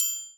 Perc (777).wav